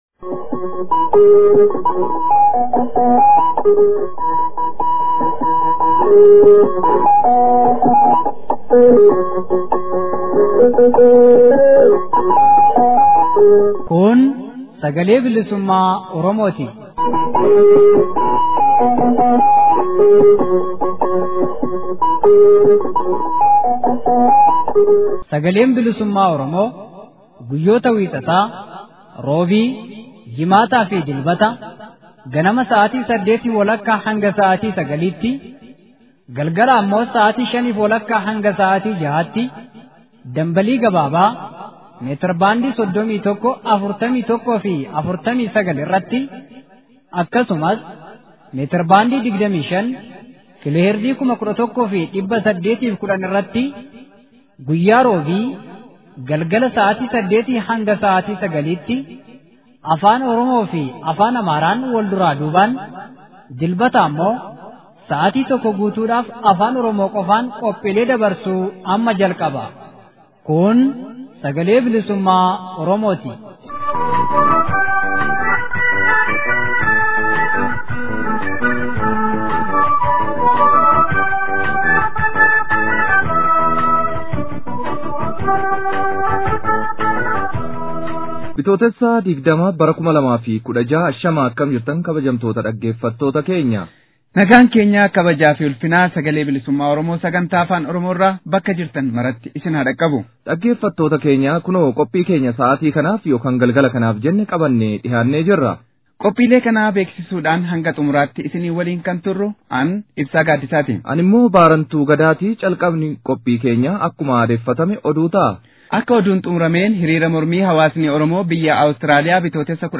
SBO Bitootessa 20,2016. Oduu, Gabaasa FXG, Sochii Hawaasa Oromoo Sa’ud Arabiyaa ilaalchisee gaaffii fi deebii qaamota adda addaa waliin, akkasumas Sochii boonsaa hawaasni Oromoo Awustiraaliyaa gochaa jiru irratti kan fuulleffate gaaffii fi deebii geggeeffame